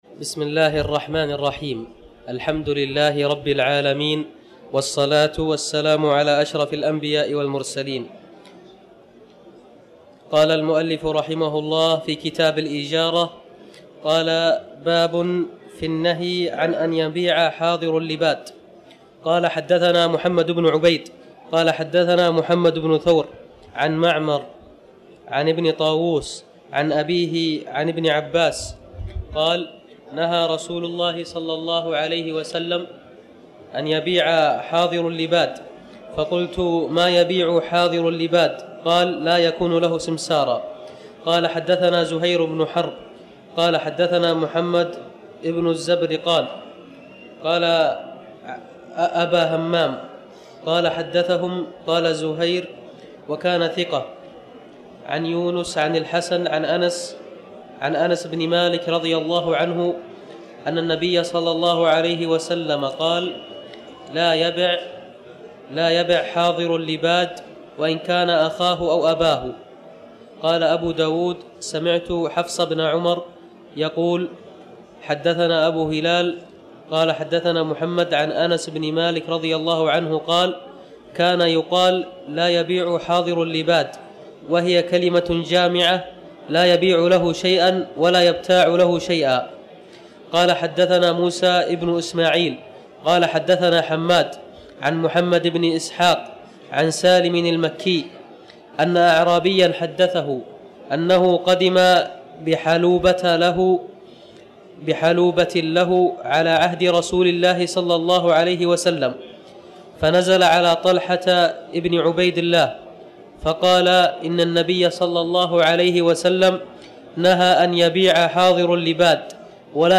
تاريخ النشر ١٧ رمضان ١٤٤٠ هـ المكان: المسجد الحرام الشيخ